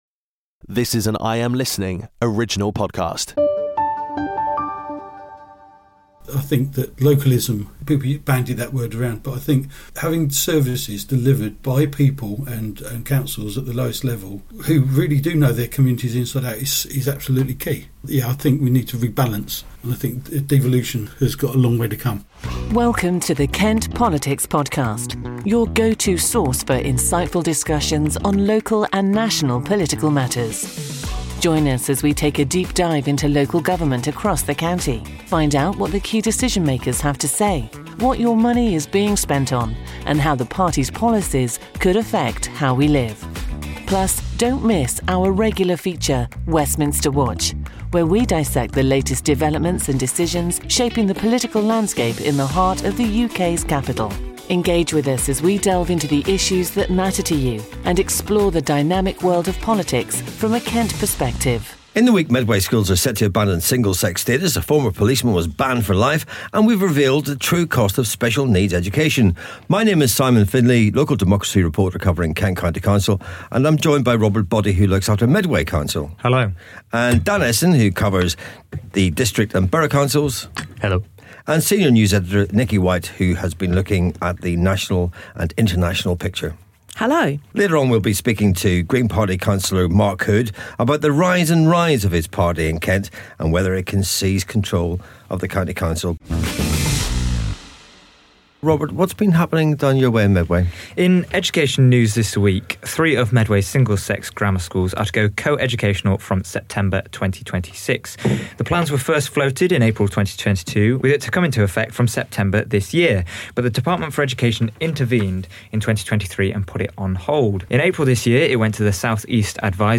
Special Feature: Interview with Green Party Councillor Mark Hood discussing: His journey into politics through grassroots activism.